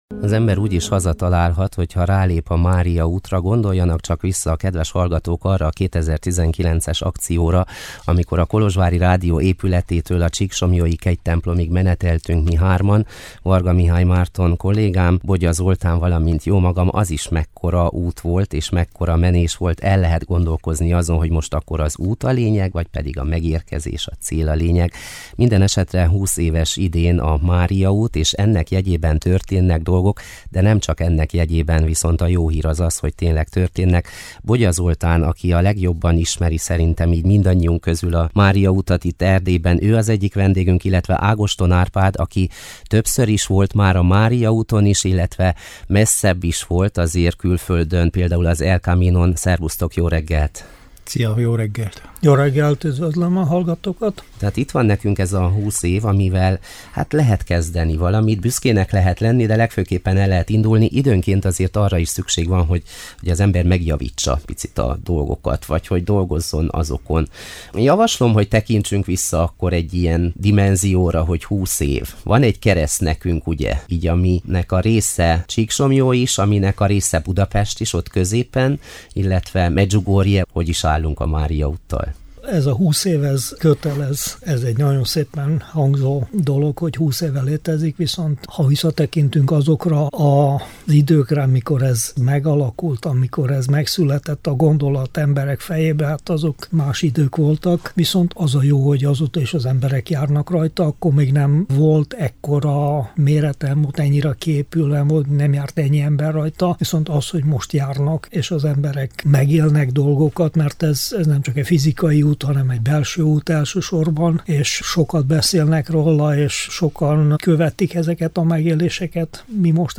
Családok, közösségek, plébániák és magánszemélyek is hozzájárulhatnak ezáltal az illető jelzőoszlop vagy útszakasz fenntartásához, fejlesztéséhez. Az interjú meghallgatható az alábbi lejátszóra kattintva.